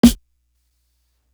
Stuck To You Snare.wav